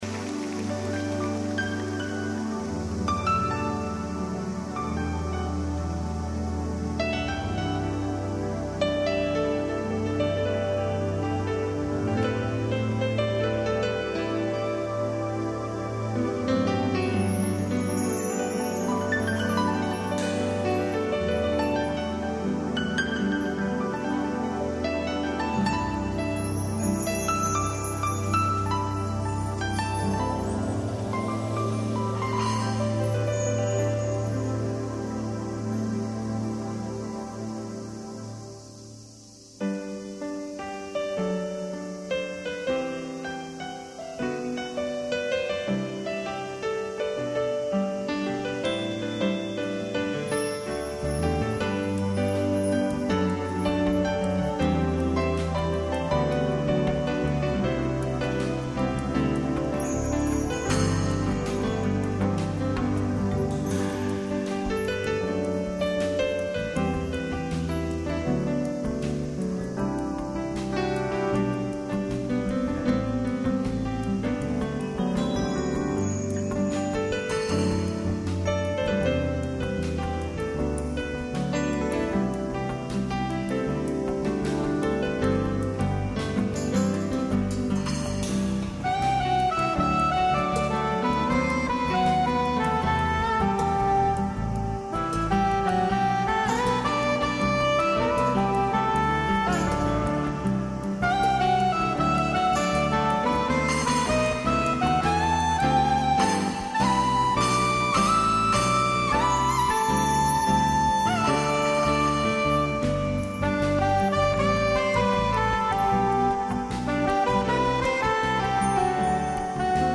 동영상 엔딩에 나오는 음원입니다
원곡과는 달리 소프라노입니다